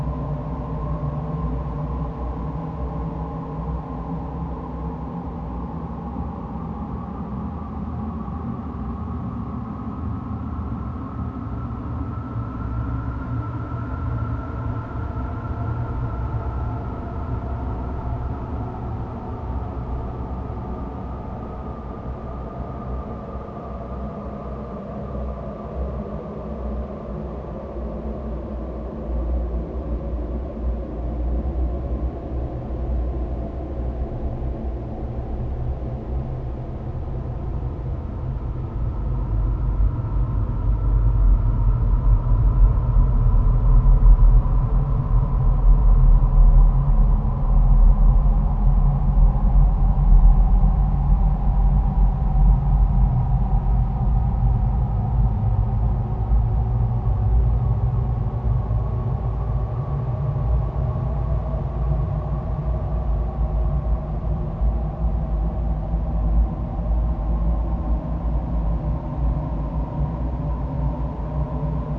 Cave_Loop_01.wav